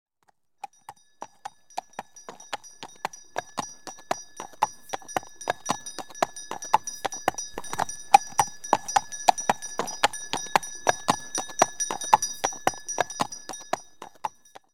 цокот копыт коня с колокольчиком